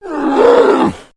Звуки гризли
Звук стонущего медведя